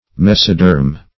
Mesoderm \Mes"o*derm\, n. [Meso- + Gr. de`rma skin.]